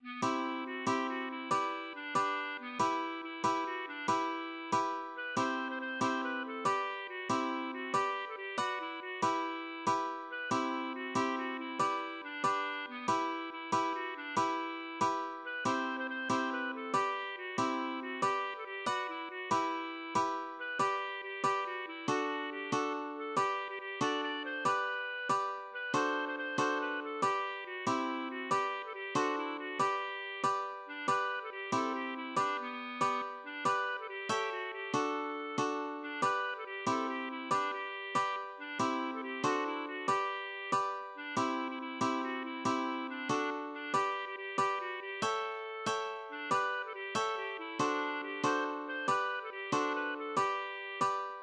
acoustic guitar (nylon)
| a4.:7 a4.:7 | g4. a4.:7 | d4.:7 d4.:7 | g4. d4.:7| g4. g4 \fine } \relative c' { \time 4/4 \key g \major \time 6/8 \tempo 4=140 \partial 8 \set Staff.midiInstrument="clarinet" b8 \repeat volta 2 { e4 fis8 g8 fis8 e8 | e4 (d8) d4 b8 | e4 e8 e8 fis8 d8 | e4. r4 b'8 | c8. c16 c8 c8 b8 a8 | g4 (fis8) e4 fis8 | g8. a16 g8 fis8 (e8) fis8 | e4. r4 b'8 | } g4 g8 g8 fis8 e8 | fis4 (g8) a8 r8 a8 | g8. g16 g8 d'8 d8 c8 | b4. r4 b8 | c8. c16 c8 c8 b8 a8 | g4 (fis8) e4 fis8 | g8. a16 g8 fis8 e8 fis8 | g4. r4 d8 | b'8. a16 g8 a8 g8 e8 | d8 b4. r8 d8 | b'8. a16 g8 g8 fis8 g8 | a4. r4 d,8 | b'8. a16 g8 a8 g8 e8 | d8 g4. r8 d8 | e8. a16 g8 fis8 e8 fis8 | g4. r4 d8 | e8. e16 e8 e8 fis8 e8 | e4 (d8) d8 r8 d8 | g8. g16 g8 g8 fis8 g8 | a4. r4 d,8 | b'8. a16 g8 a8 g8 e8 | d4 (g8) c8 r8 c8 | b8. a16 g8 d8 b'8 a8 | g4. r4 \fine } \addlyrics { \set stanza = "I. " Ich saß mal auf ei- nem Stei- ne Und dach- te wohl lan- ge nach Wie lang _ schon wäh- ret al- lei- ne Das Hart- stee- ner Un- ge- mach.